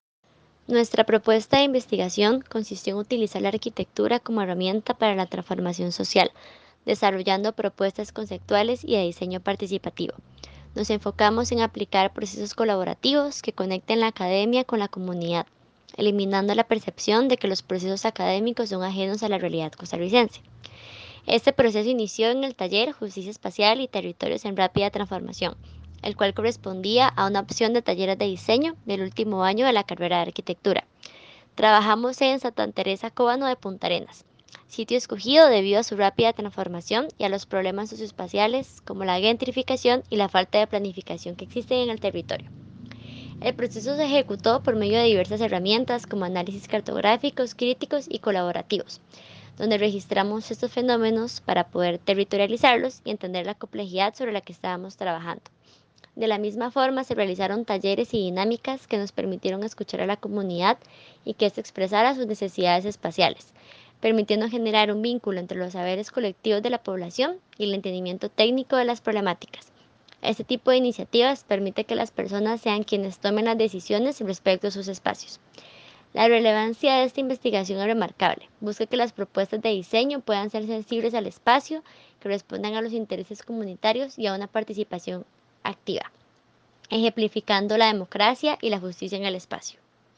Entrevista estudiante ganador de la XI edición estudiantil de la Bienal Internacional de Arquitectura